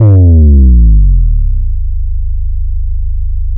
808 - SLIDE HOME.wav